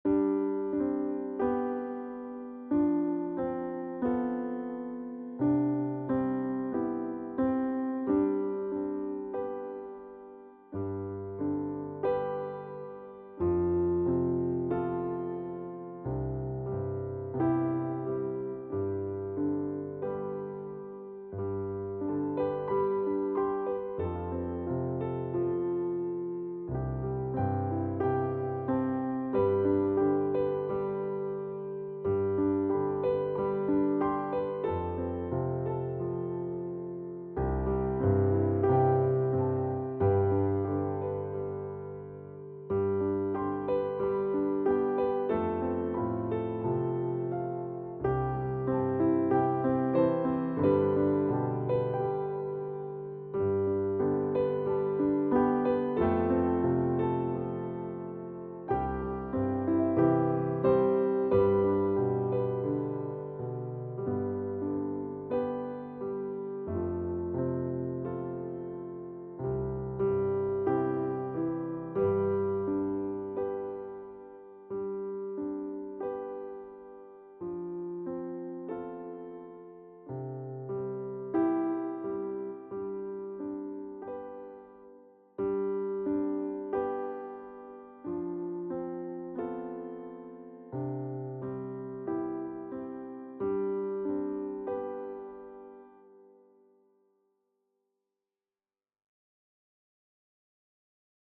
Dog Cat Bird 2 MP3 Audio Backing Track